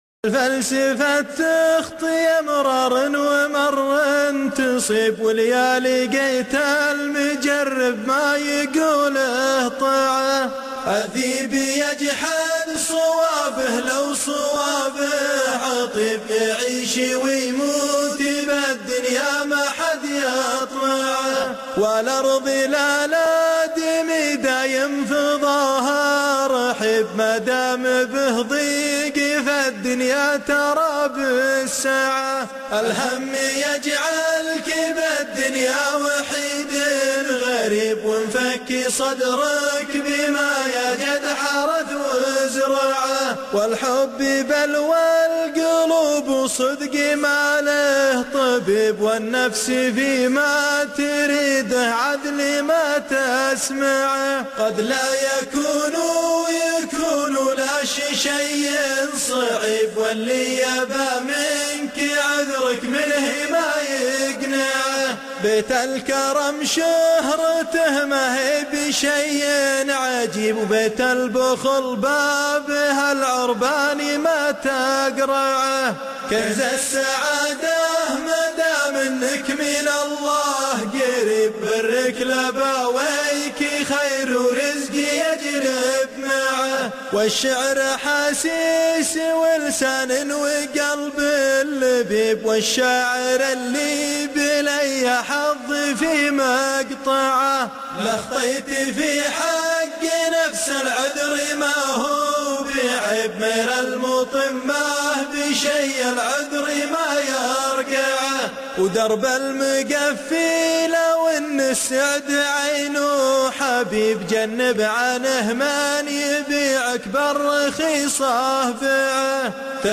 الشيلات -> شيلات منوعه 7